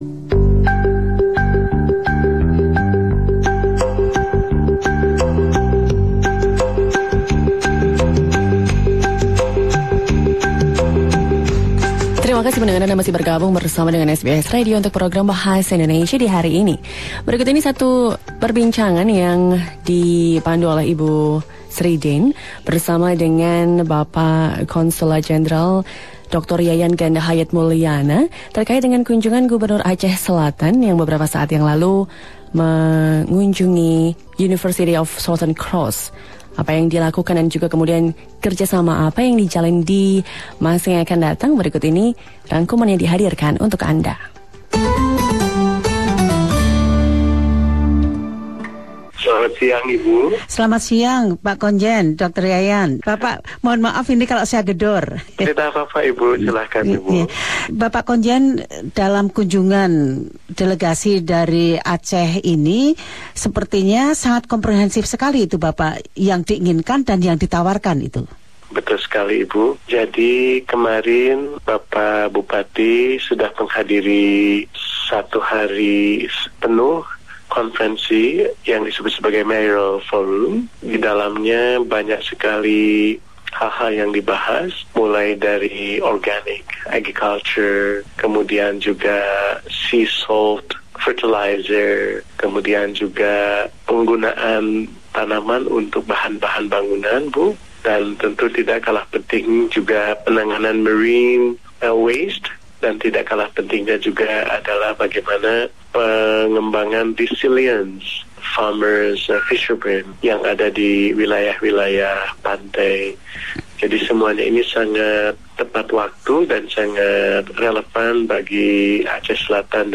KONJEN RI Sydney, Dr Yayan GH Mulyana berbicara tentang pentingnya kunjungan dari delegasi Kabupaten Aceh Selatan untuk membangun kerjasama Indonesia-Australia.